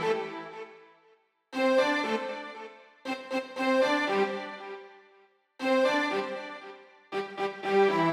28 Strings PT4.wav